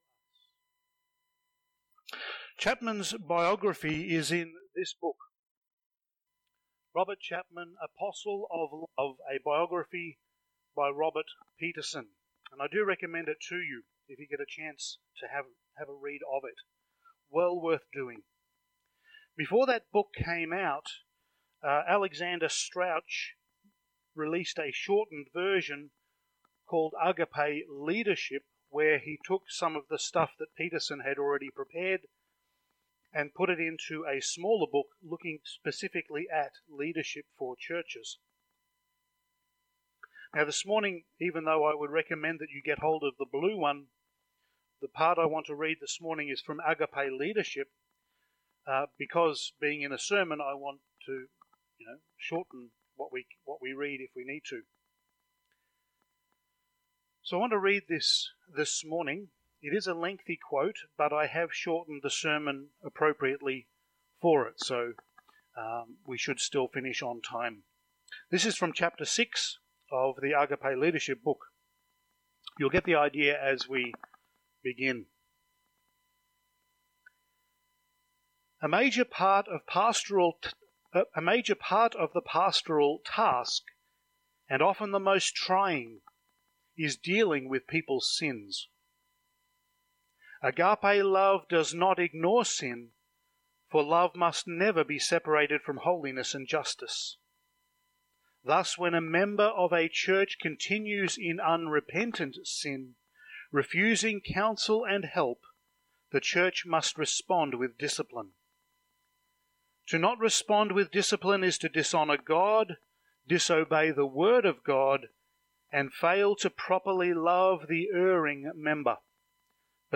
Passage: Acts 6:1-7 Service Type: Sunday Morning